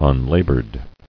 [un·la·bored]
Un*la"bored , a. 1.